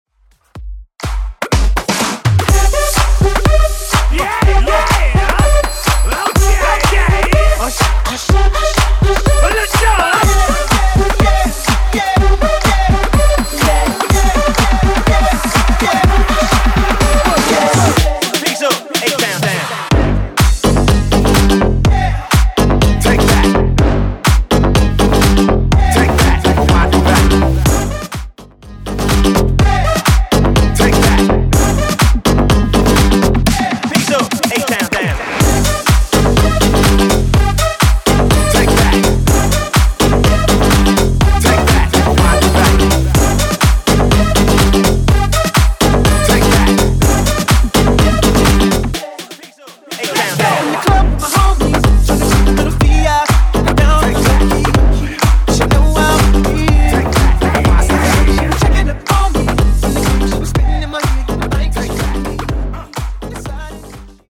90's , R & B , RE-DRUM 98 Clean